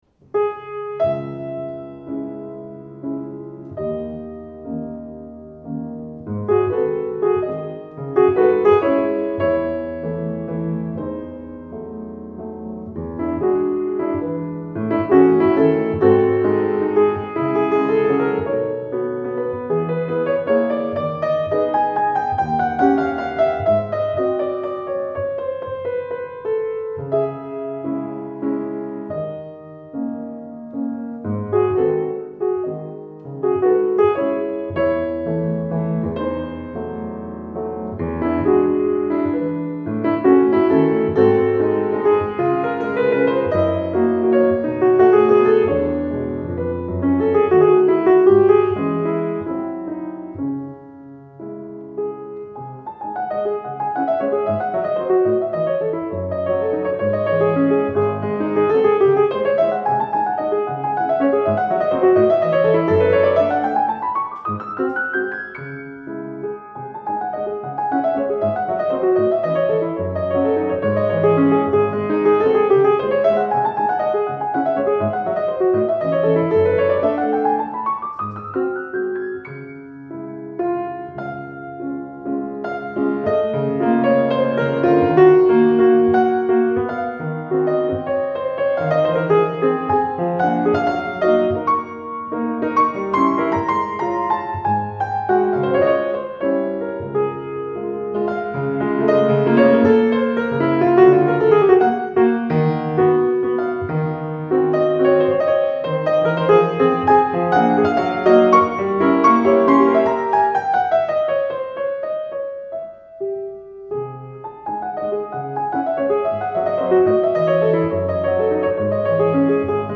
Klassik